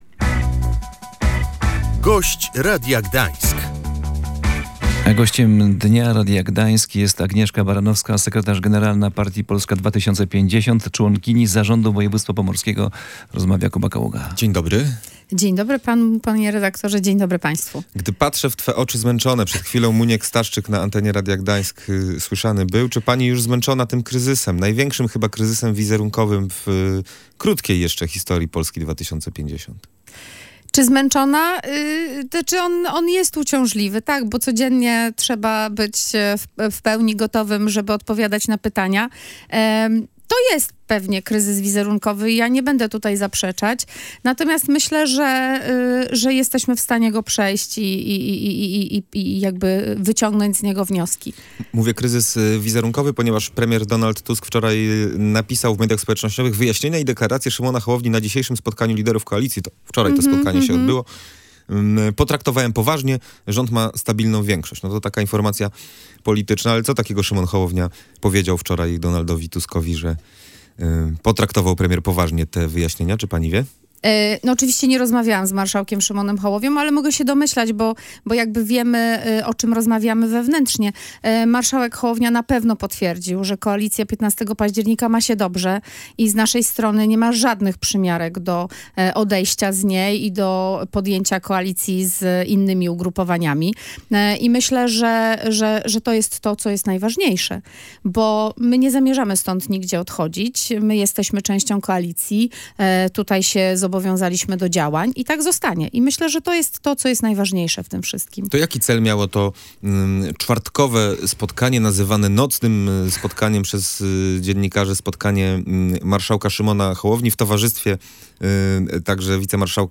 Agnieszka Baranowska była Gościem Radia Gdańsk.